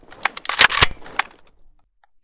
ak12_unjam.ogg